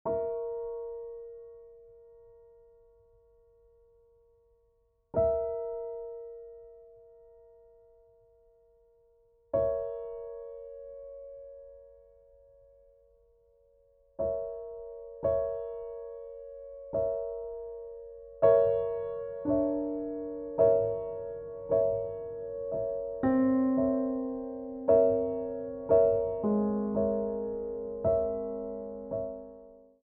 for piano solo
Description:Classical music; solo work
Instrumentation:Piano solo